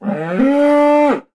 Index of /HCU_SURVIVAL/Launcher/resourcepacks/HunterZ_G4/assets/minecraft/sounds/mob/cow
say1.ogg